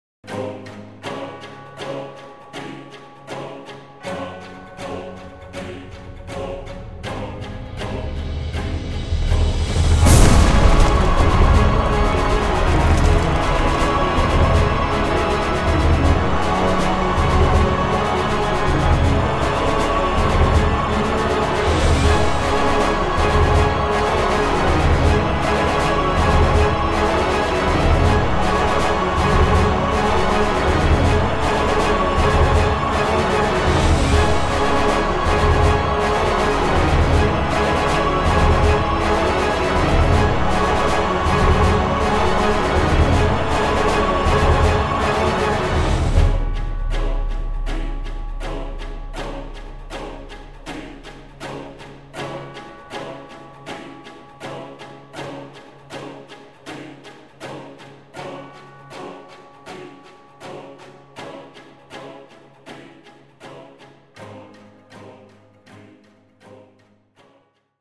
PixelPerfectionCE/assets/minecraft/sounds/mob/wither/spawn.ogg at mc116